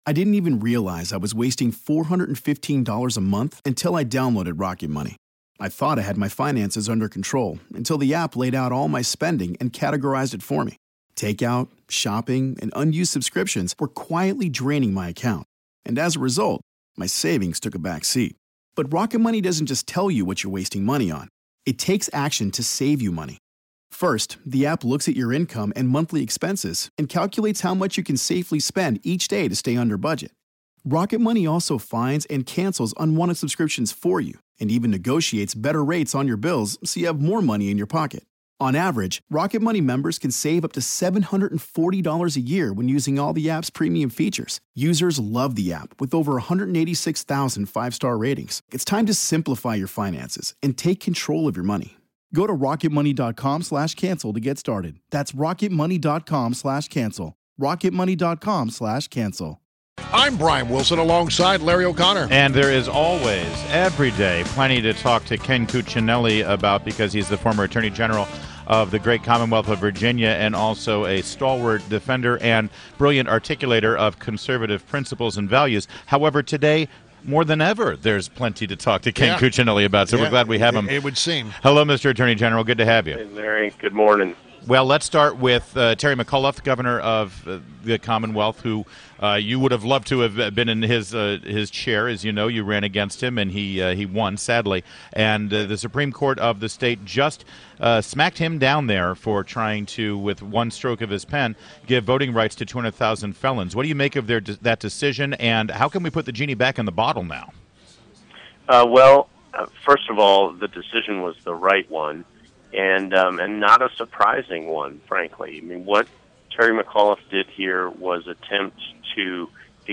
WMAL Interview - Ken Cuccinelli- 07.25.16